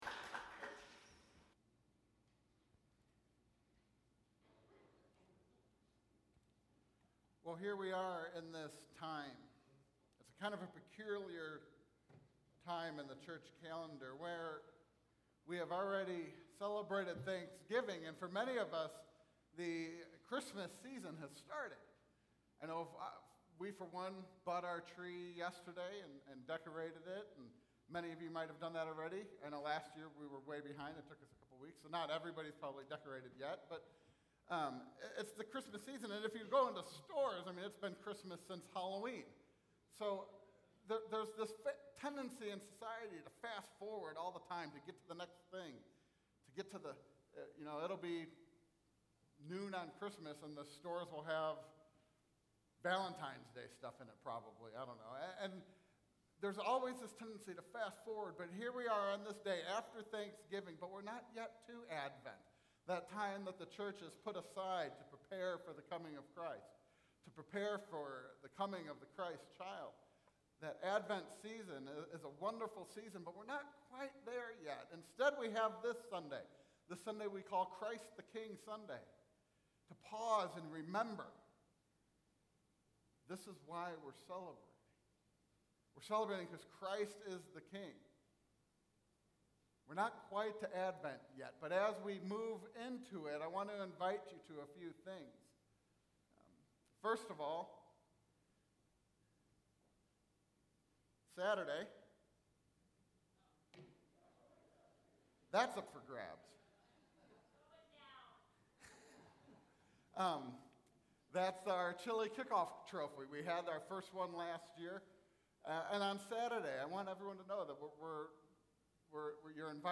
Follow this link to hear a sermon called “So, Is Jesus King?”
This version was performed in worship by the Riverside Church youth praise team, OMG (Our Mighty God).